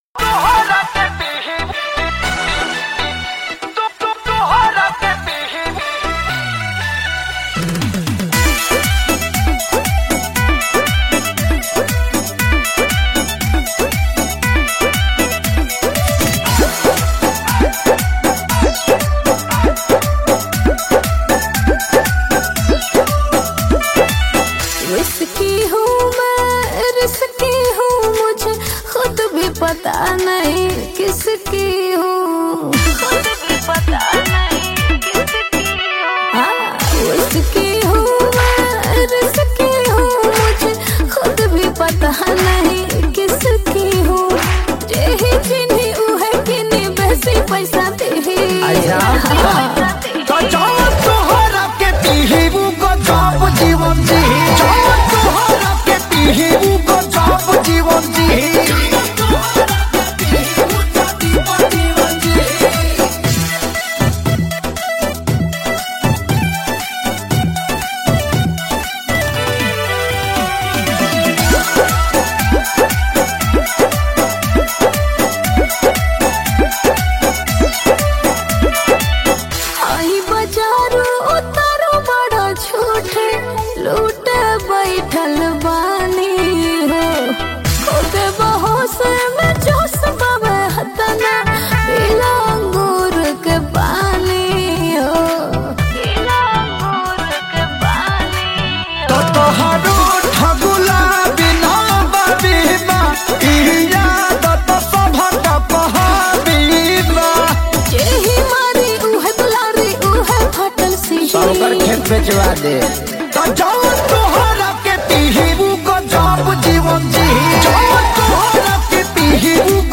New New Bhojpuri Song 2023